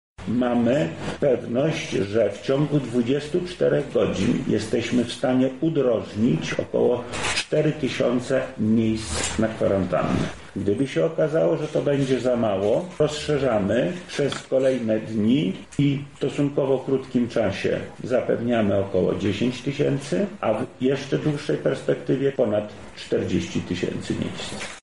Jesteśmy przygotowani również na sytuację, w której zwiększyłaby się liczba zakażonych osób – mówi Wojewoda Lubelski Lech Sprawka: